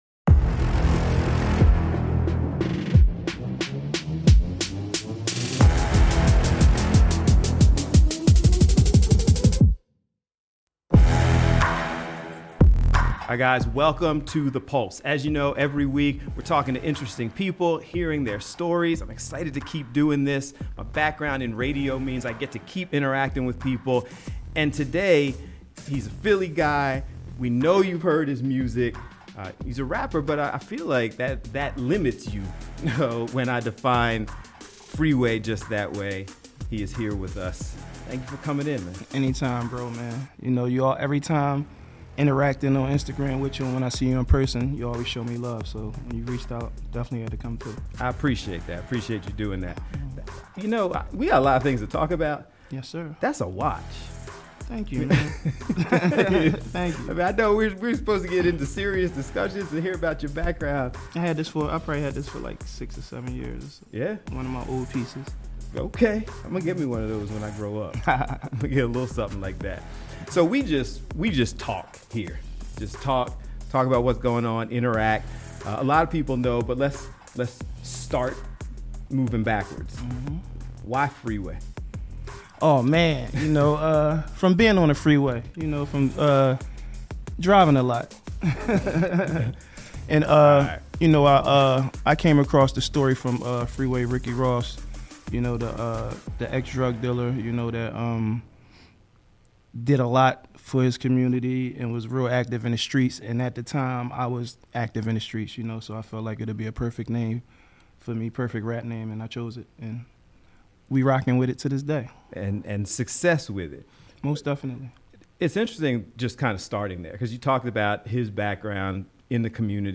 topical discussions and interviews surrounding current issues, events, and entertainment.